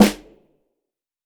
TC2 Snare 10.wav